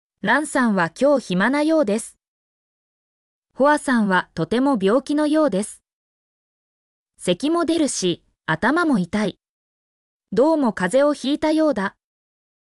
mp3-output-ttsfreedotcom-3_xDeml5fI.mp3